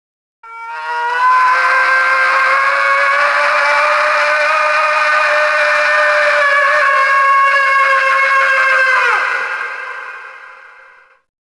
Крик в игровом мире